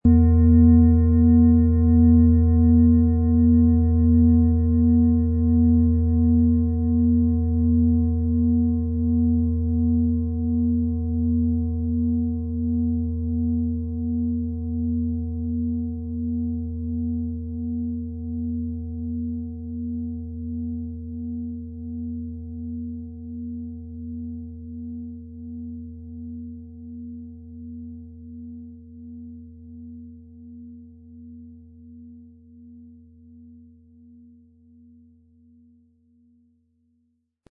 Es ist eine von Hand gearbeitete tibetanische Planetenschale Hopi-Herzton.
Unter dem Artikel-Bild finden Sie den Original-Klang dieser Schale im Audio-Player - Jetzt reinhören.
Lieferung mit richtigem Schlägel, er lässt die Planetenschale Hopi-Herzton harmonisch und wohltuend schwingen.
PlanetentöneHopi Herzton & Mond
MaterialBronze